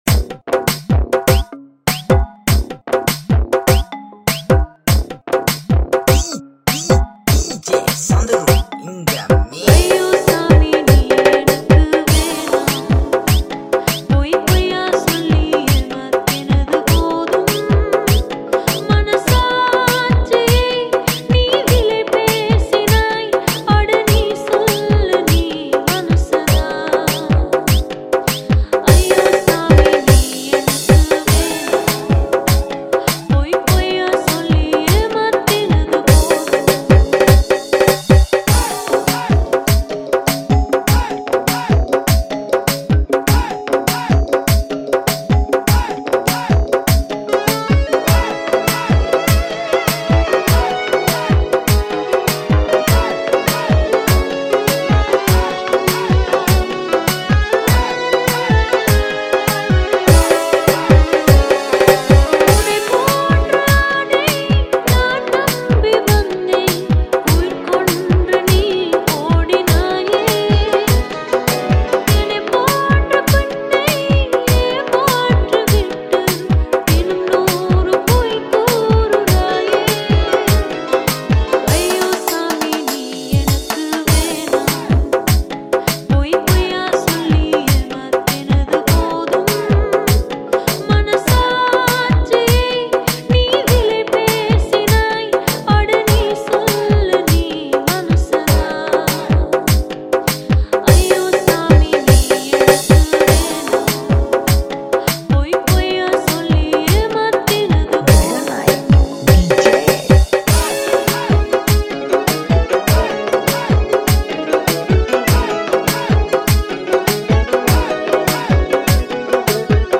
High quality Sri Lankan remix MP3 (3.1).